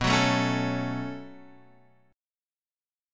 B7sus2 chord